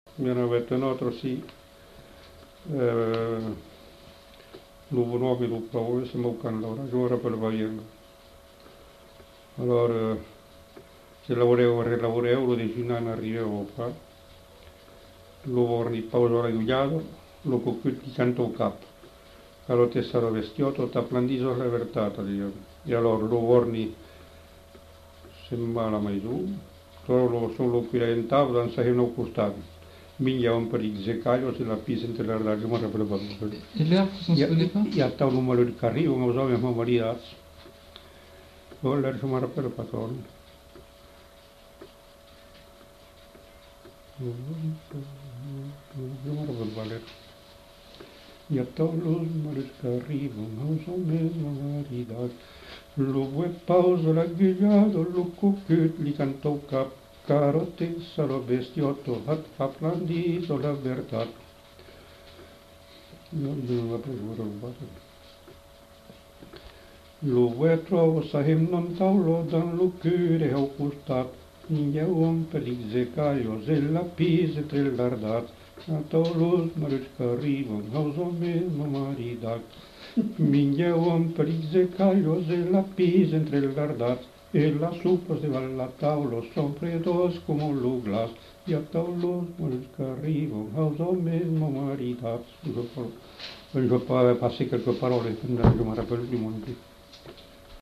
Genre : chant
Effectif : 1
Type de voix : voix d'homme
Production du son : chanté ; récité
Danse : rondeau